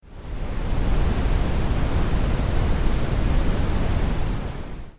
• Built-in sound therapy with 10 relaxing sound modes:
Fan Noise
FanNoise.mp3